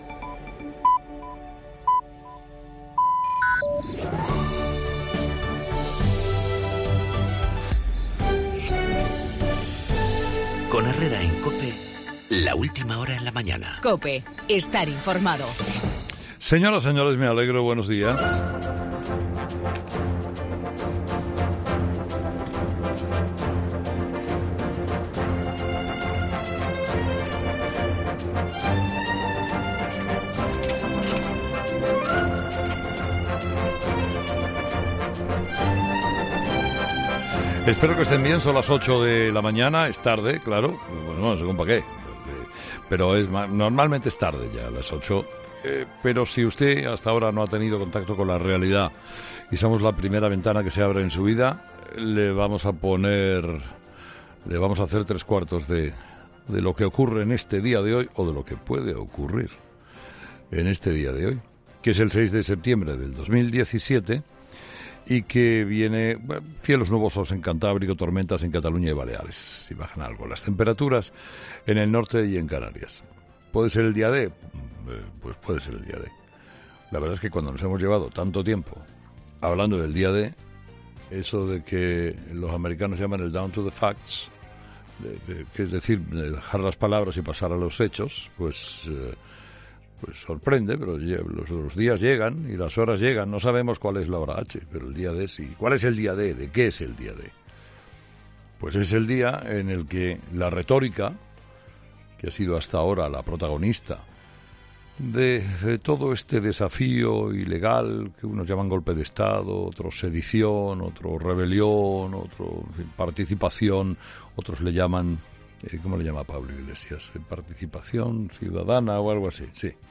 AUDIO: La exclusiva sobre el censo sanitario que quiere utilizar la Generalidad para el 1-O, en el monólogo de Carlos Herrera a las 8 de la mañana.